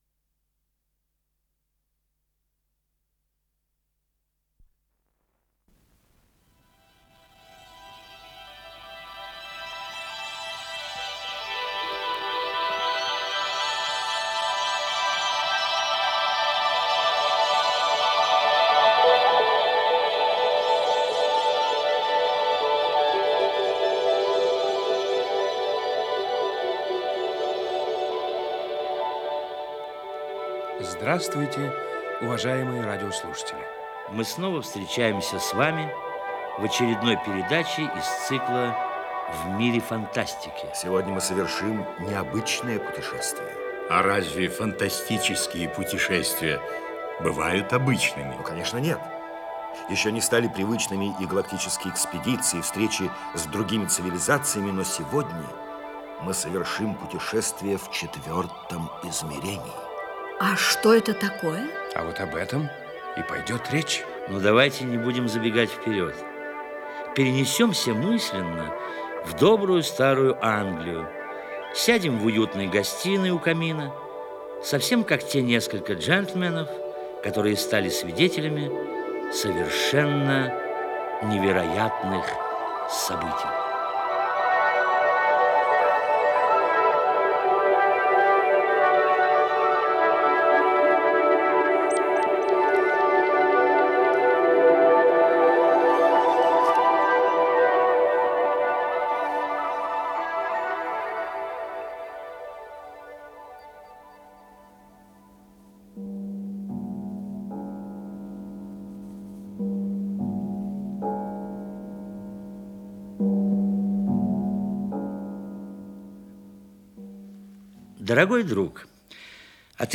Исполнитель: Артисты ленинградских театров
Радиопостановка